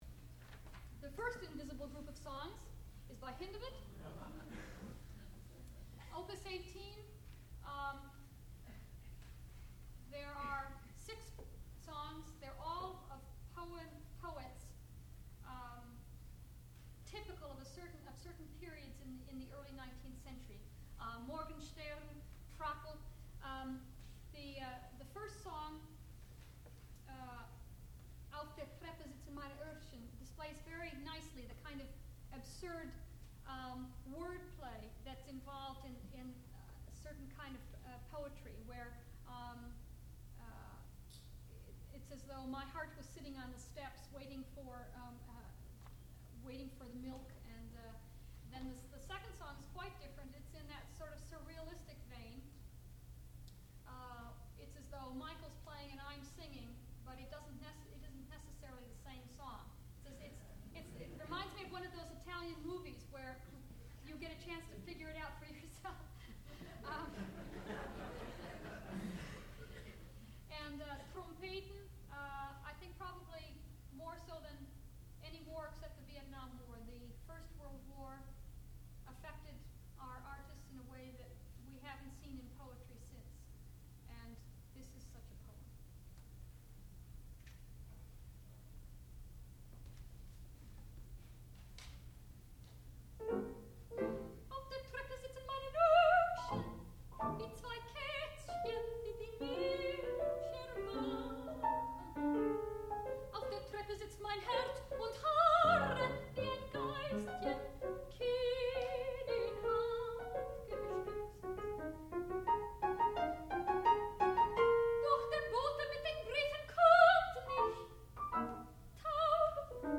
sound recording-musical
classical music
piano
soprano